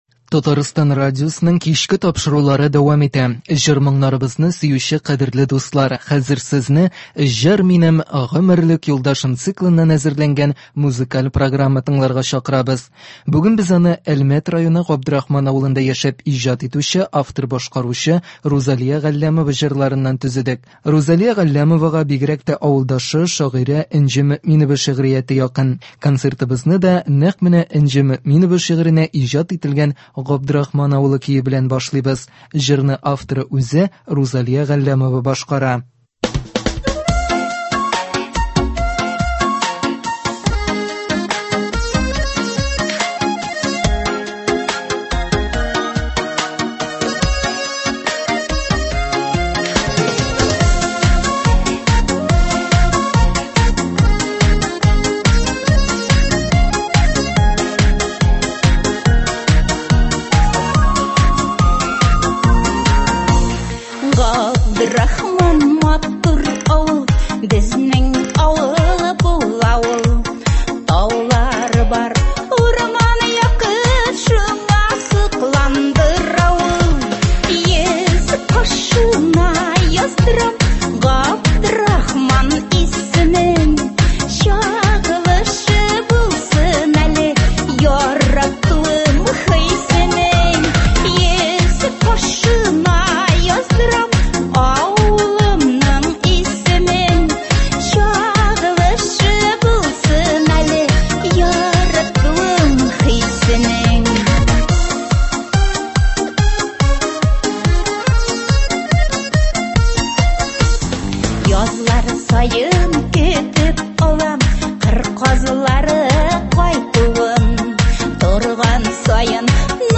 Үзешчән башкаручылар чыгышы.
Концерт (25.03.24)